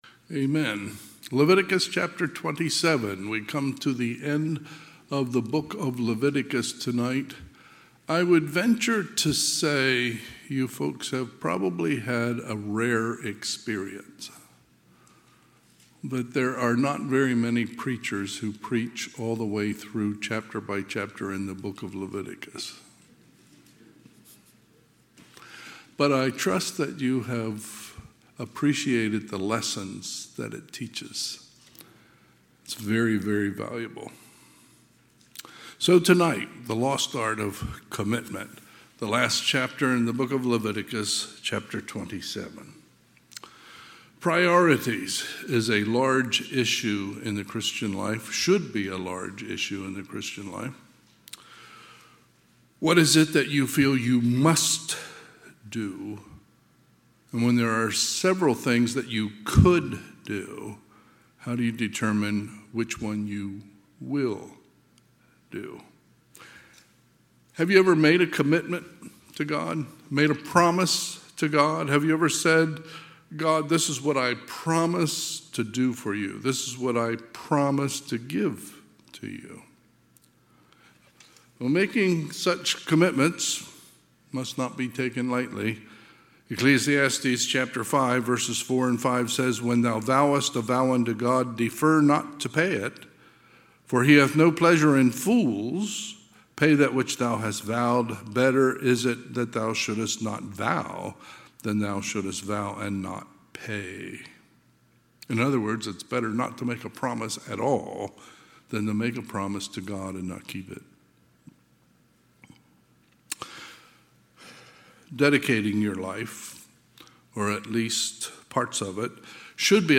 Sunday, November 3, 2024 – Sunday PM
Sermons